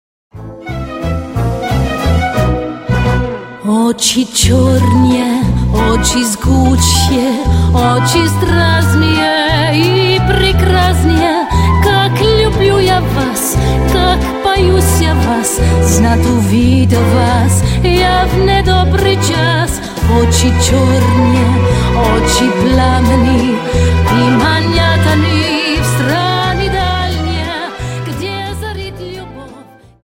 Dance: Viennese Waltz 58